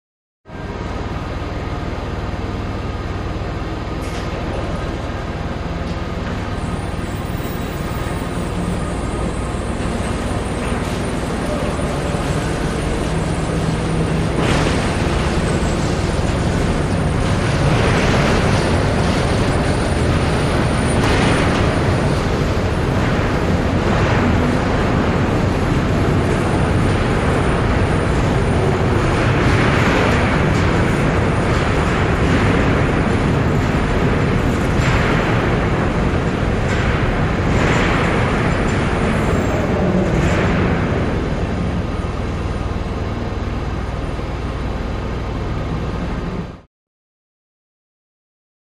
Steel Mill Background; Steel Mill Ambience; Motor / Fan Noise, Chain Noise, Distant Clanks, Squeaks, Medium Perspective.